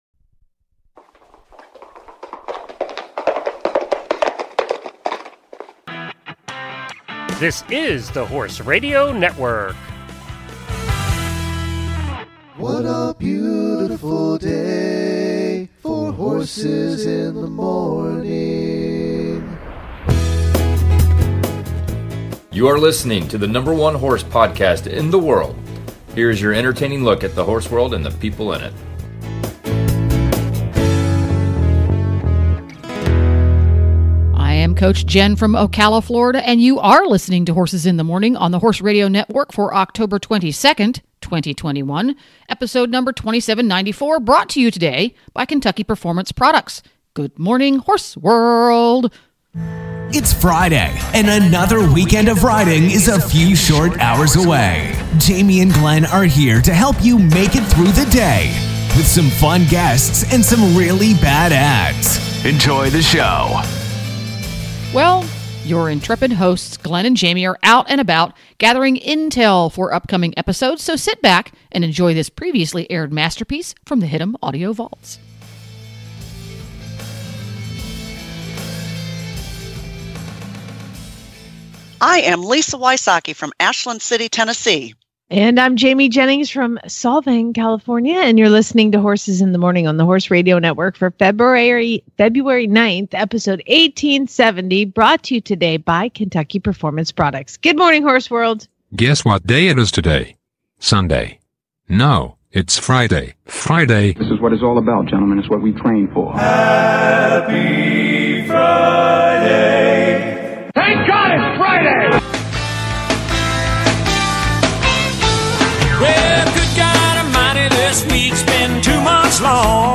Monty Roberts answers listener questions